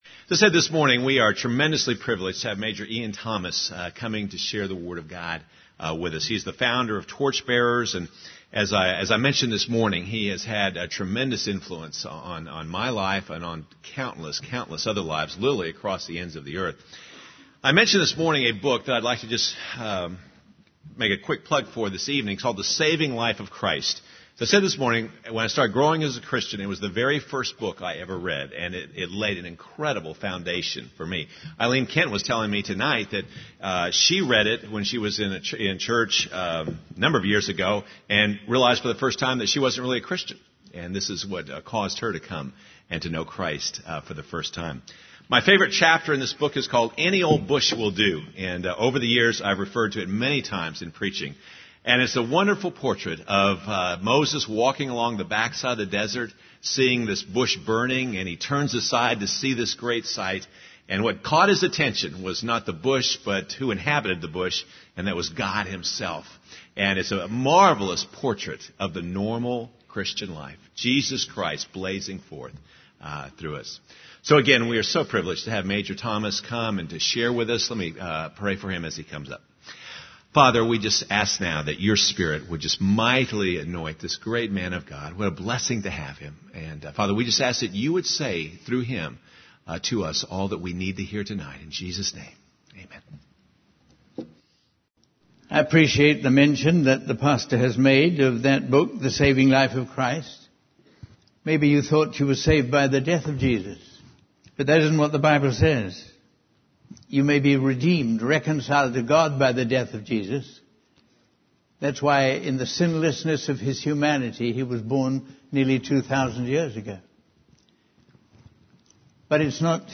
In this sermon, the preacher emphasizes the power and significance of the resurrection of Jesus Christ. He highlights how the early church was transformed by their rediscovery of a risen and living Savior. The message of the early church centered around the resurrection and the indwelling presence of Christ.